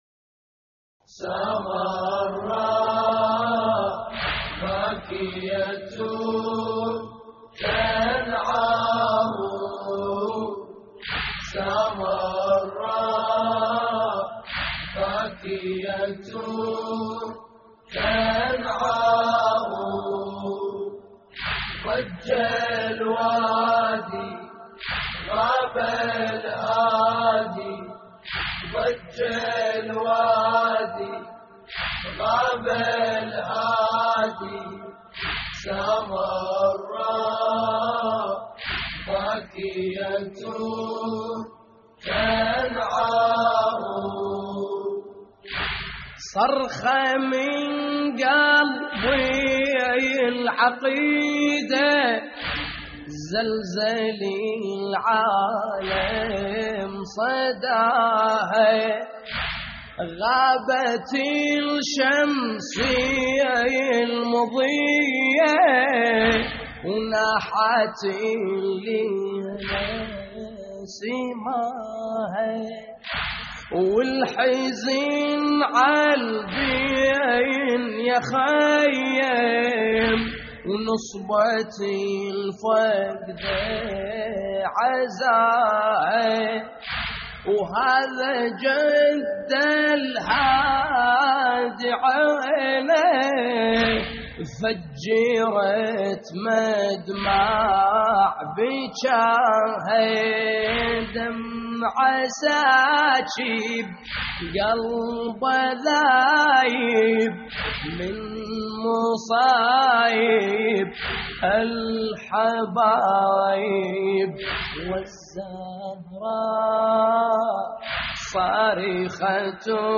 اللطميات الحسينية
موقع يا حسين : اللطميات الحسينية سامراء باكية تنعاه ضج الوادي غاب الهادي - استديو «سامراء» لحفظ الملف في مجلد خاص اضغط بالزر الأيمن هنا ثم اختر (حفظ الهدف باسم - Save Target As) واختر المكان المناسب